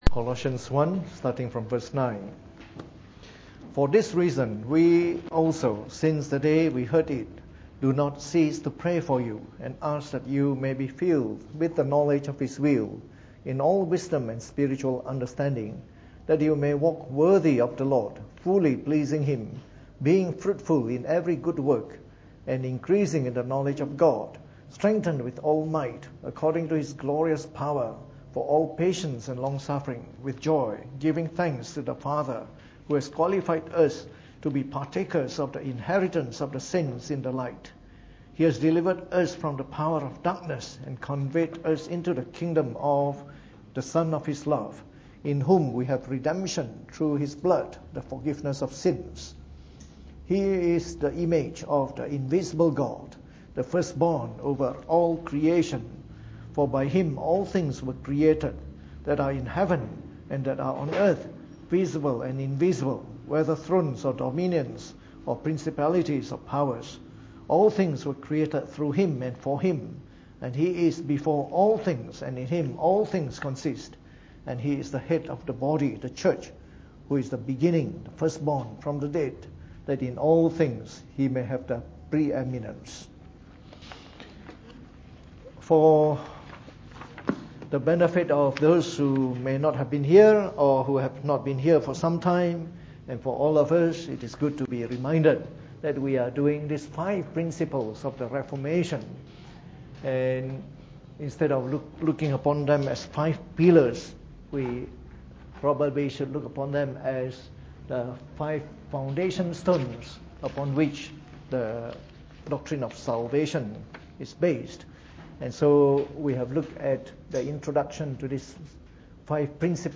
Preached on the 16th of November 2016 during the Bible Study, from our series on the Five Principles of the Reformed Faith.